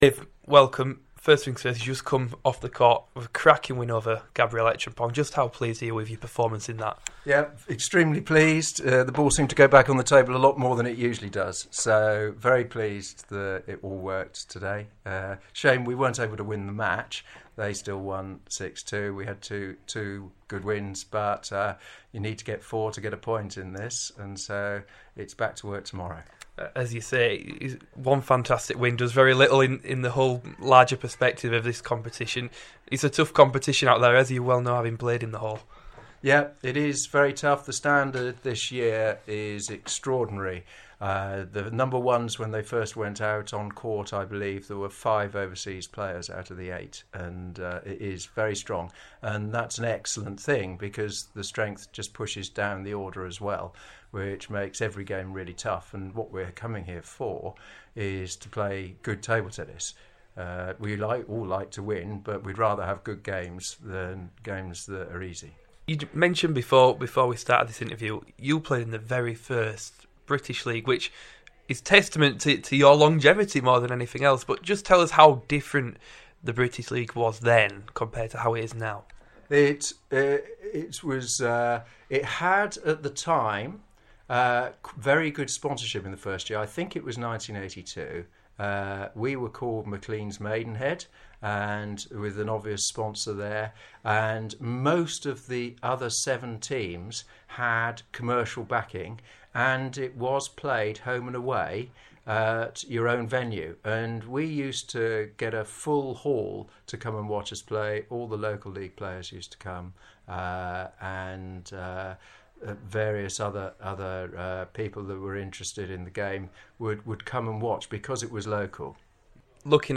Feature Interview